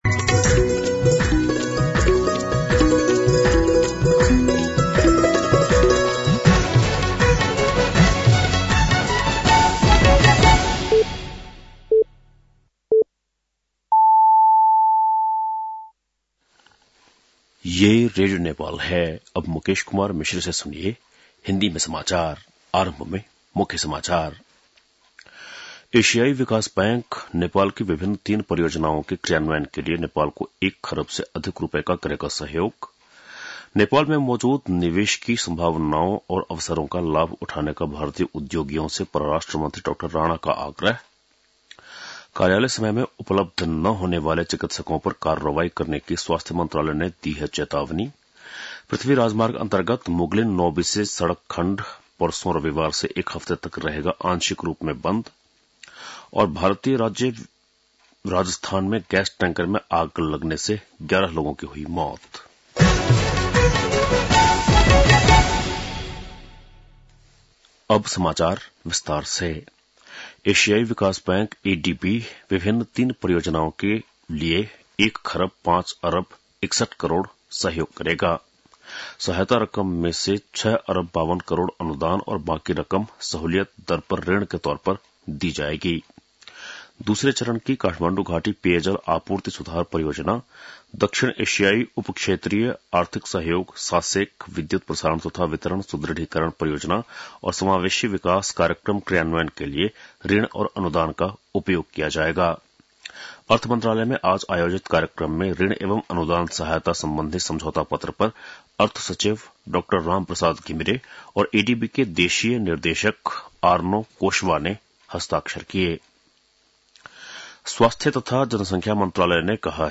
बेलुकी १० बजेको हिन्दी समाचार : ६ पुष , २०८१
10-PM-Hindi-News-9-5.mp3